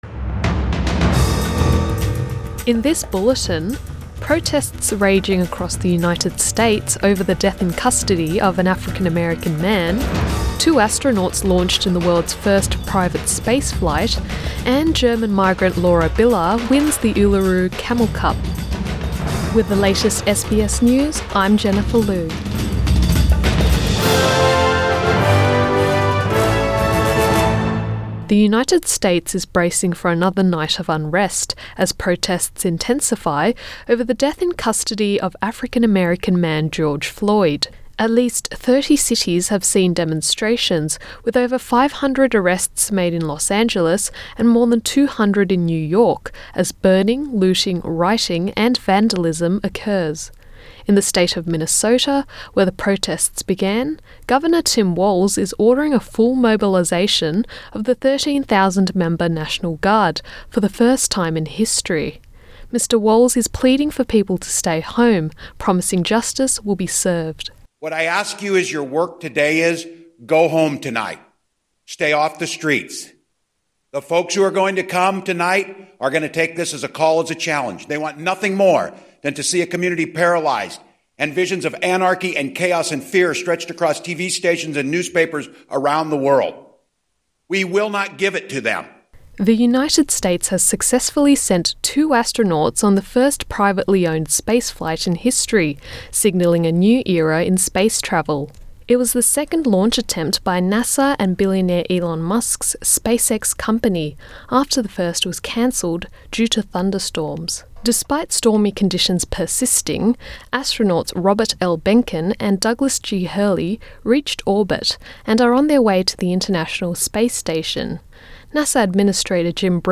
AM bulletin 31 May 2020